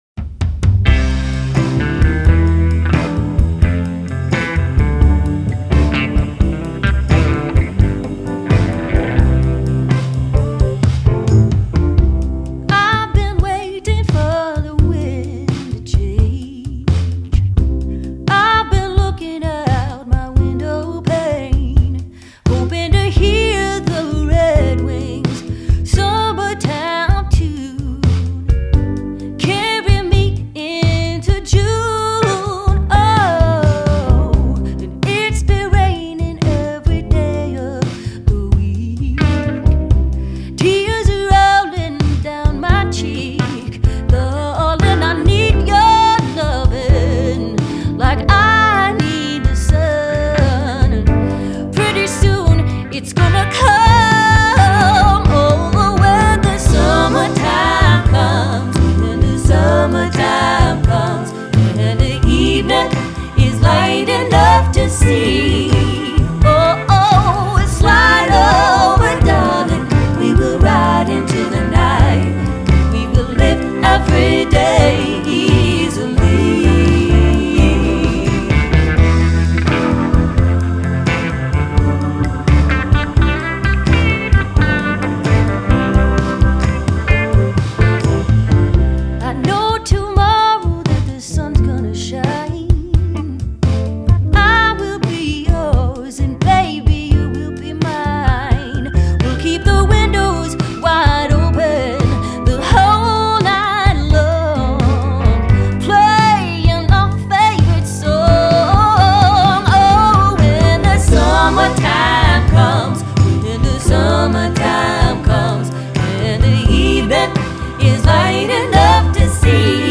first full studio album